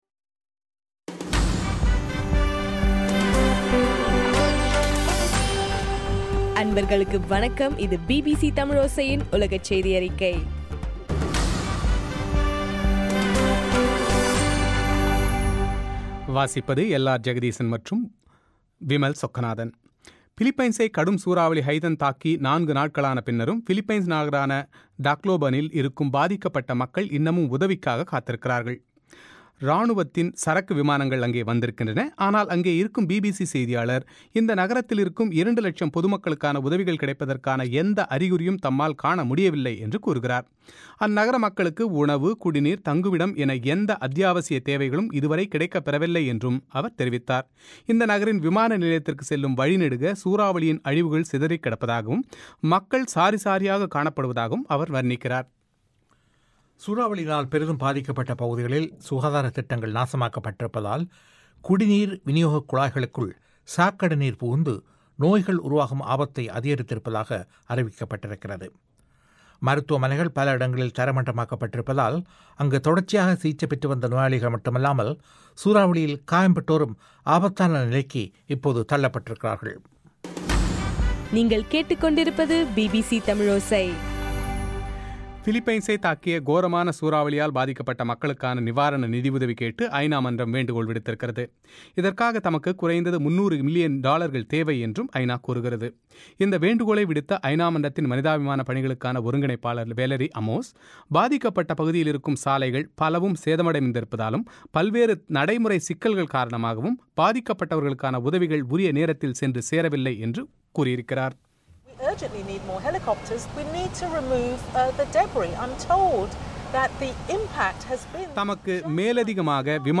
நவம்பர் 12 பிபிசி தமிழோசை உலகச் செய்தி அறிக்கை